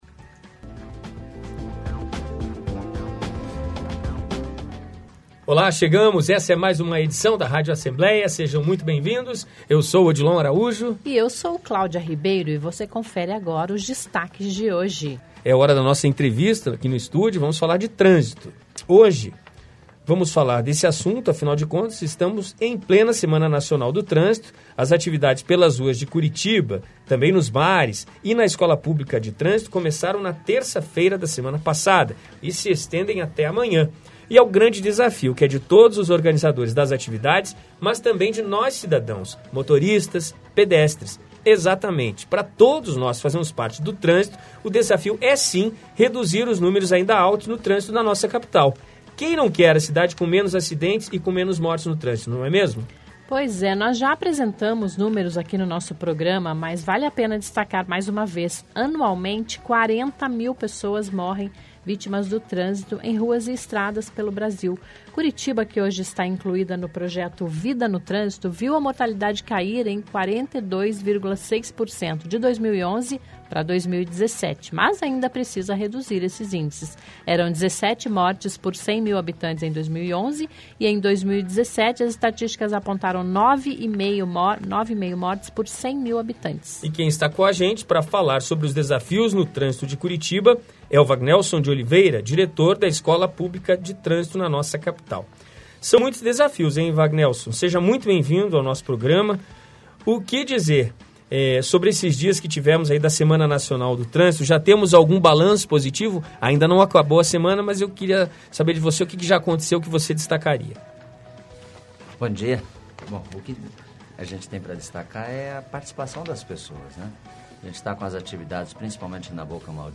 Os desafios do trânsito estão na nossa entrevista desta segunda(24).